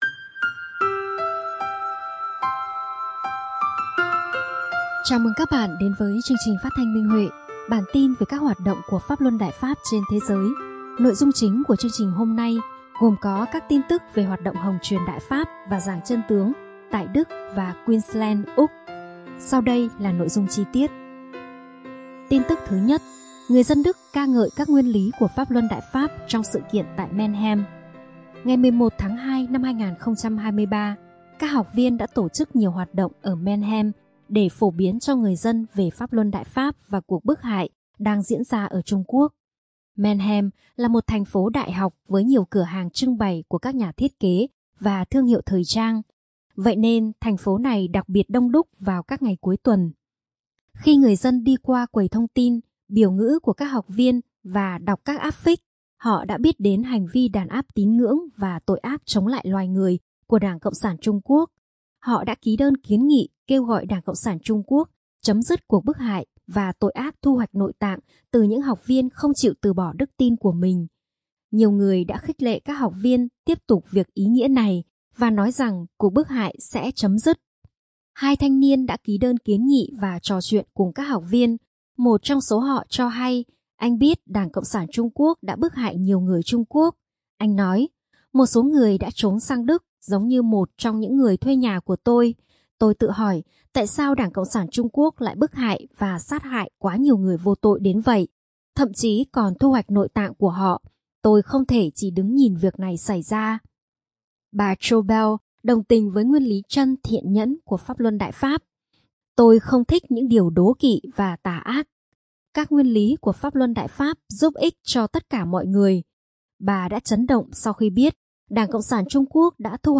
Chào mừng các bạn đến với chương trình Phát thanh Minh Huệ – bản tin về các hoạt động của Pháp Luân Đại Pháp trên thế giới. Nội dung chính của chương trình hôm nay gồm có các tin tức về hoạt động hồng truyền Đại Pháp và giảng chân tướng tại Đức và Queensland, Úc.